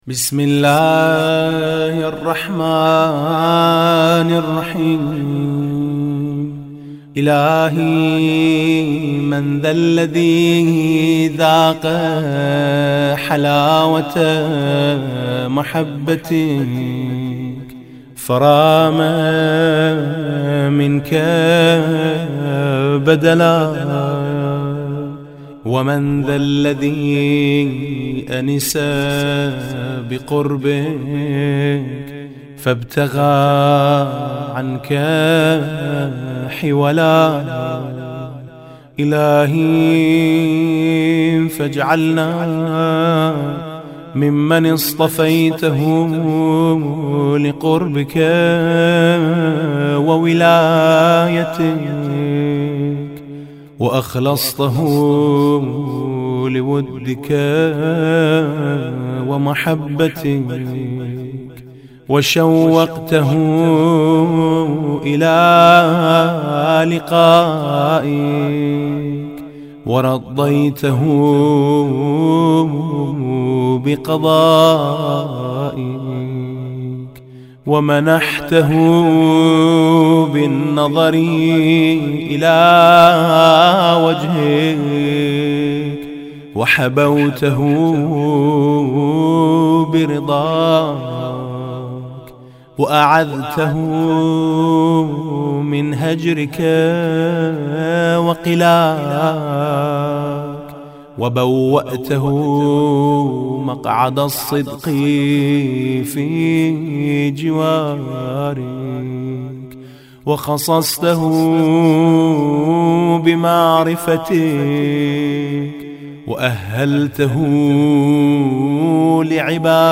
مناجات شیفتگان و عاشقان یا مناجات المحبین با لحن عربی از میثم مطیعی